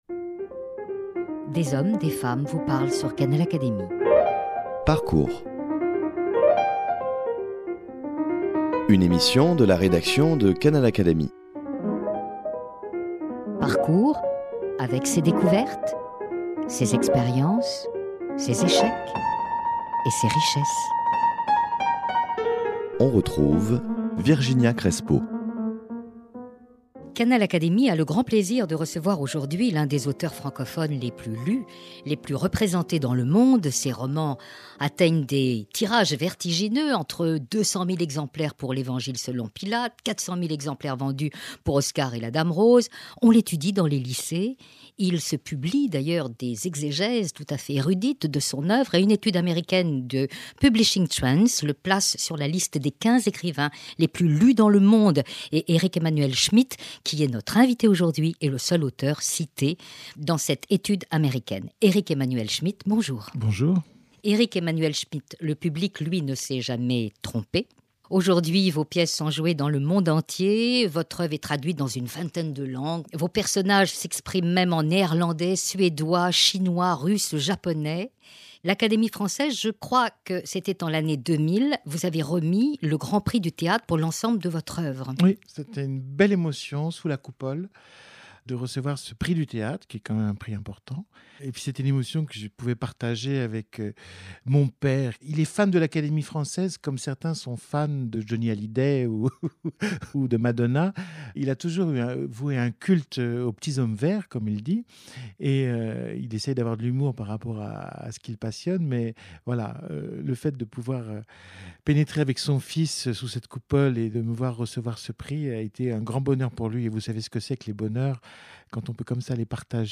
Canal Académie reçoit l’un des auteurs francophones les plus lus et les plus représentés dont les pièces sont jouées dans le monde entier et l’œuvre traduite en plus de vingt langues : Eric-Emmanuel Schmitt, philosophe, écrivain, auteur de théâtre, metteur en scène de cinéma.